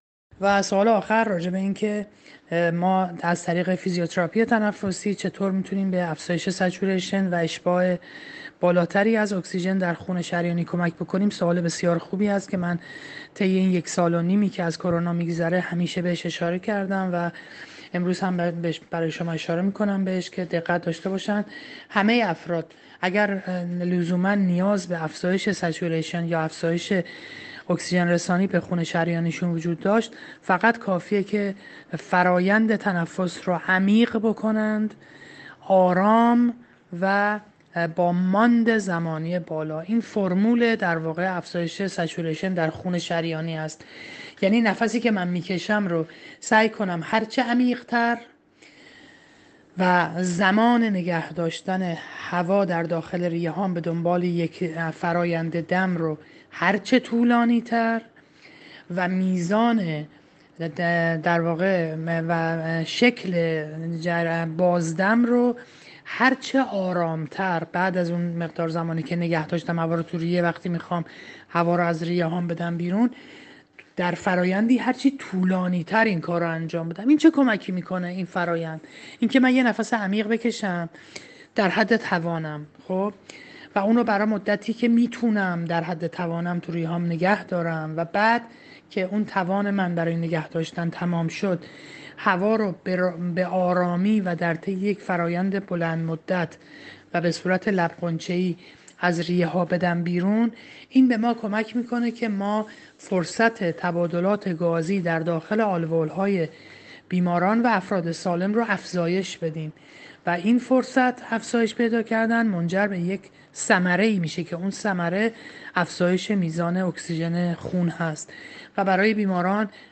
عضو هیات علمی دانشگاه علوم پزشکی جندی شاپور اهواز در گفتگو با جام جم آنلاین توضیح داد